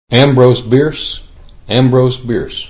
Discussion Topics Ambrose Bierce is pronounced: Right-click to download sound as mp3 Hosted by Metanet
pronun.mp3